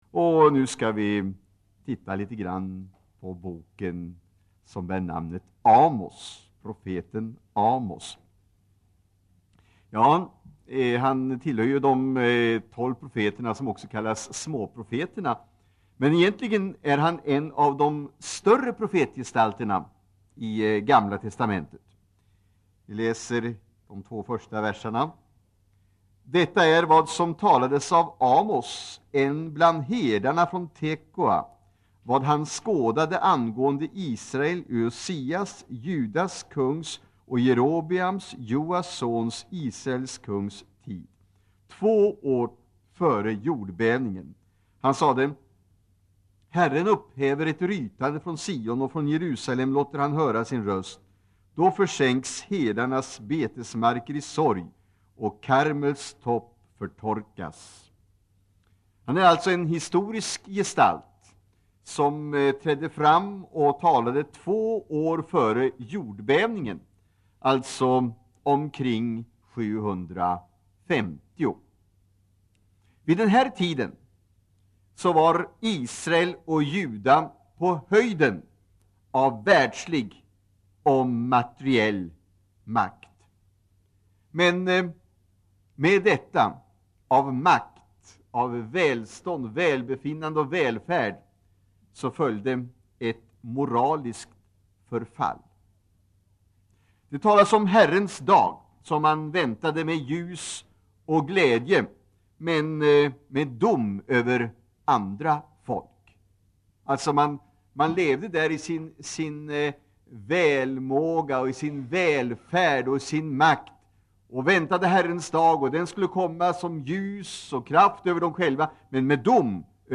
Inspelad i Citykyrkan, Stockholm 1985-12-18.